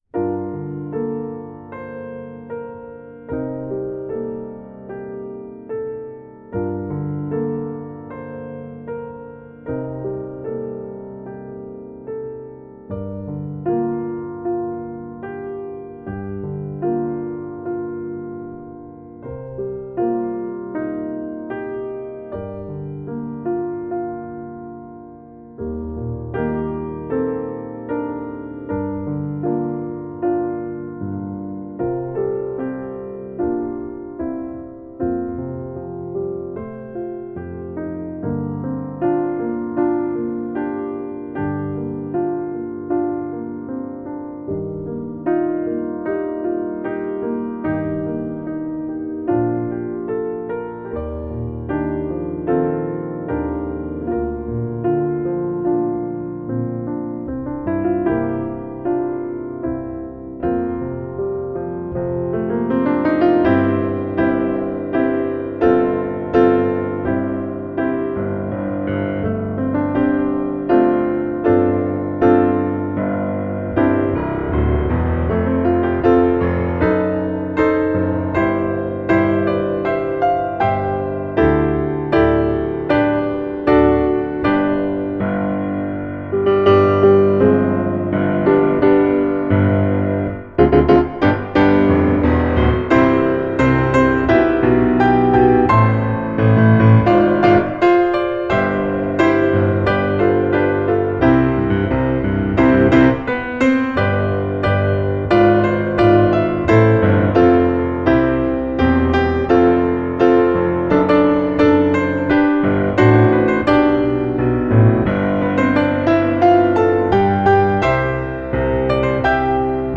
Voicing: Piano Collection